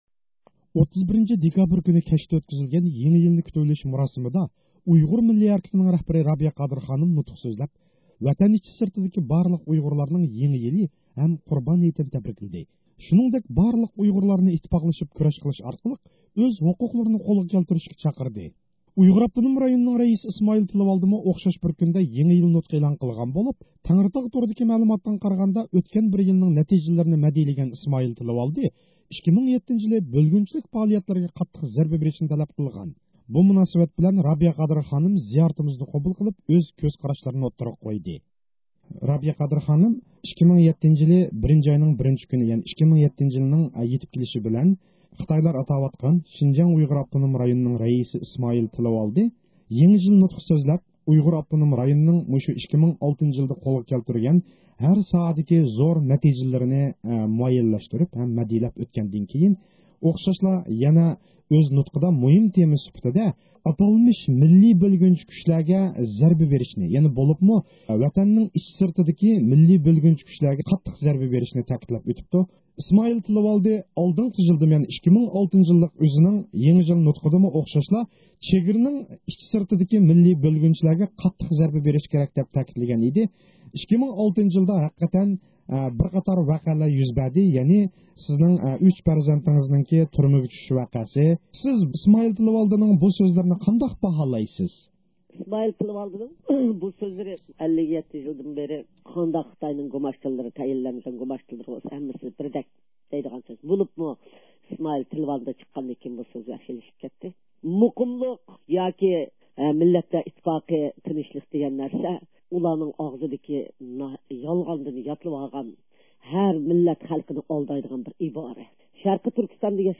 بۇ مۇناسىۋەت بىلەن رابىيە قادىر خانىم زىيارىتىمىزنى قوبۇل قىلىپ، ئۆز كۆز قاراشلىرىنى ئوتتۇرىغا قويدى.